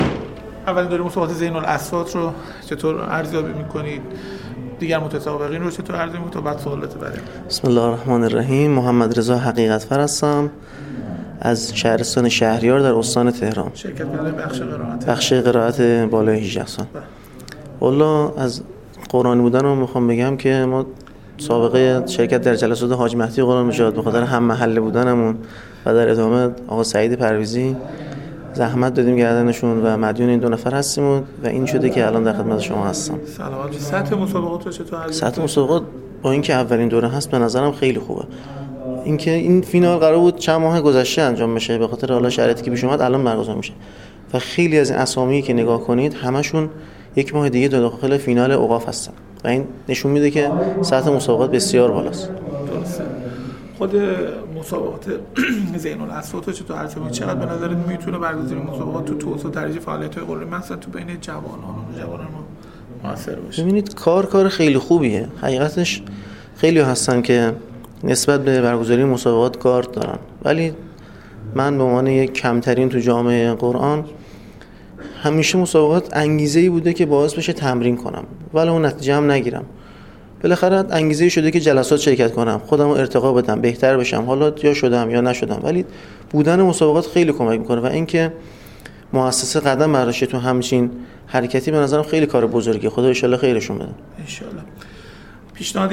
ایکنا در گفت‌وگویی صمیمی با این قاری جوان، از چگونگی پیمودن این مسیر، ارزیابی او از این رویداد نوپا و نقش آن در انگیزه‌بخشی به نسل جوان پرسیده است.